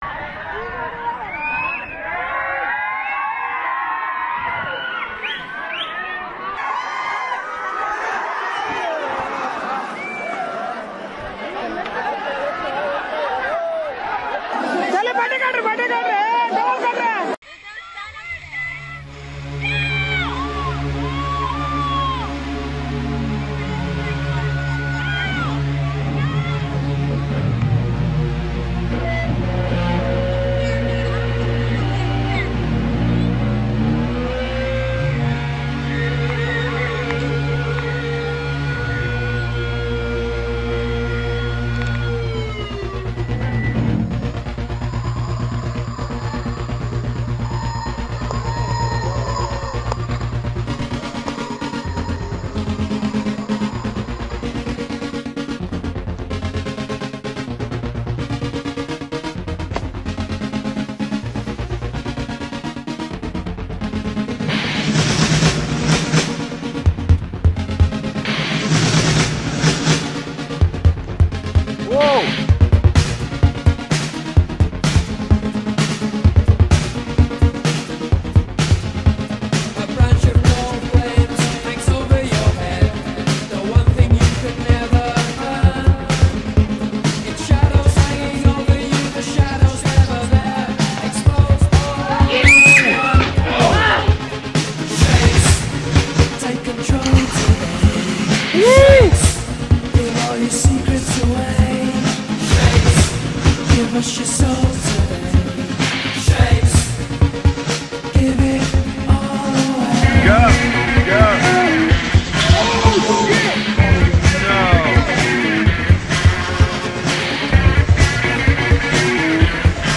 Music: Tones on Tail - performance